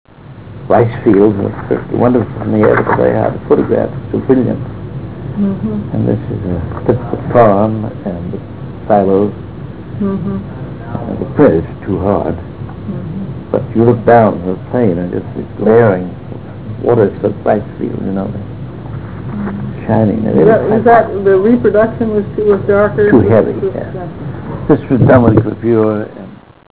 215Kb Ulaw Soundfile Hear Ansel Adams discuss this photo: [215Kb Ulaw Soundfile]